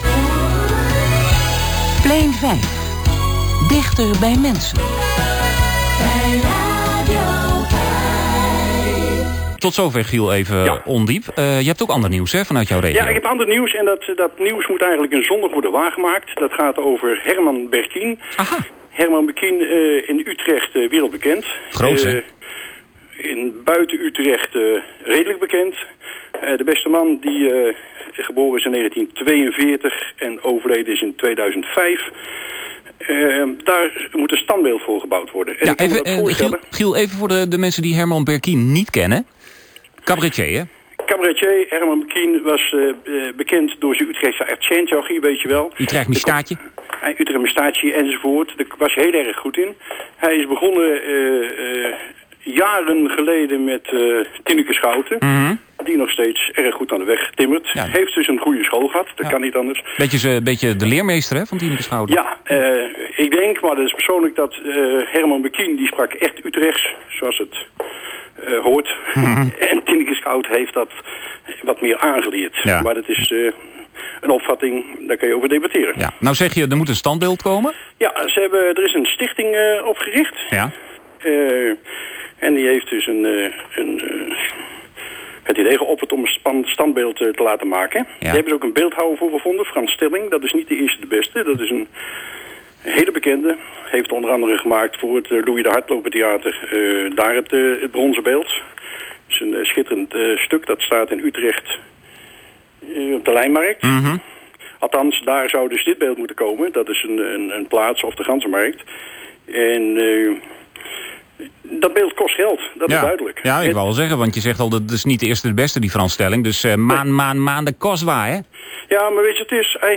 kort radio interview